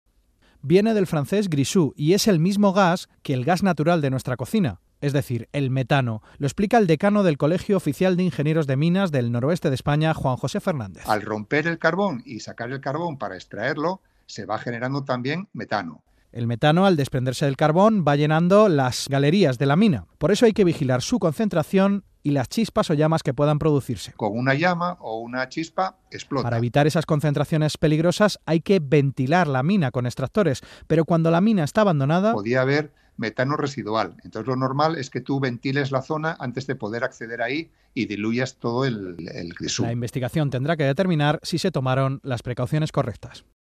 … y en Hora 25 de la Cadena SER: